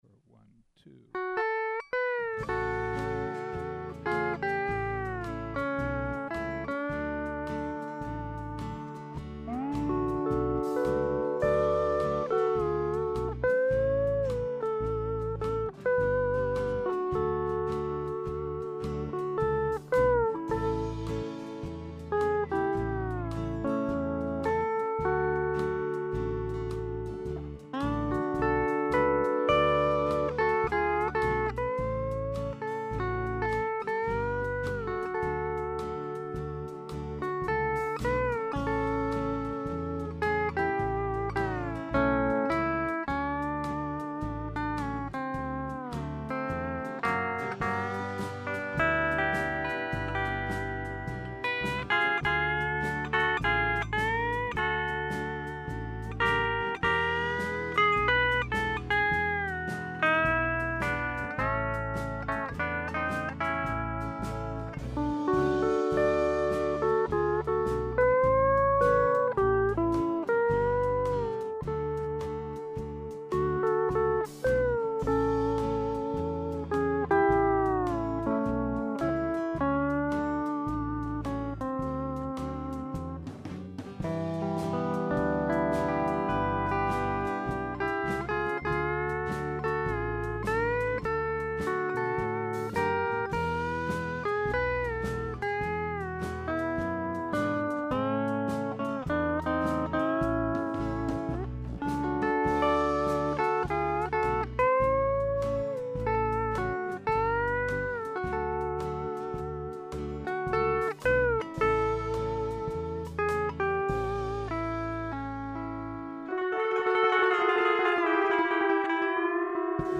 Here's a recording of four pedal steels, each plugged directly into the recorder with no volume pedal or effects.
For those who want to play the game, there are 7 sections: intro A A B A B A, and then the ending (which doesn't count).
I just made this recording to demonstrate how different the actual tones coming from the guitars are.
There are clearly distinct tonal differences, and while some of them could be masked to some degree by picking position in relation to the bridge and attack strength, most (if nnot all) would still be different enough to be noticeable.